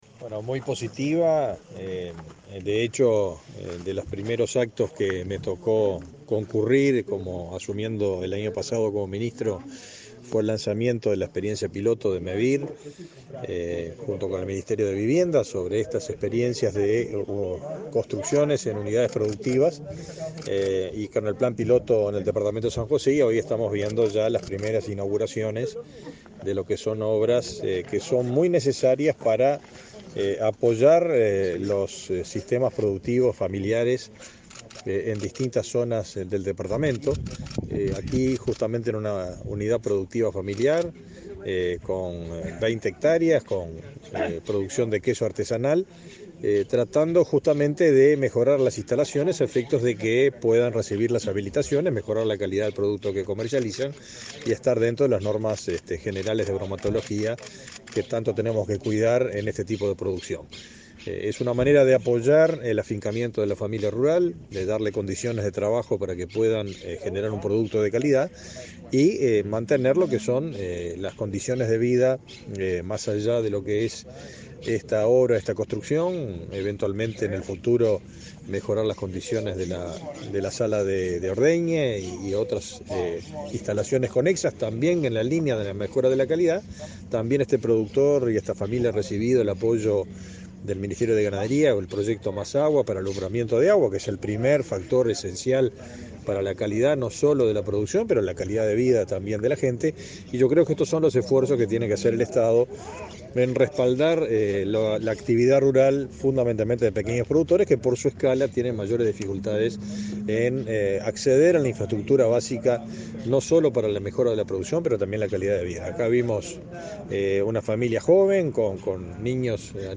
Declaraciones a la prensa del ministro de Ganadería, Fernando Mattos
Declaraciones a la prensa del ministro de Ganadería, Fernando Mattos 19/07/2022 Compartir Facebook Twitter Copiar enlace WhatsApp LinkedIn El presidente de Mevir, Juan Pablo Delgado, y el ministro Fernando Mattos recorrieron varias intervenciones para la producción rural en el departamento de San José. Luego, Mattos dialogó con la prensa.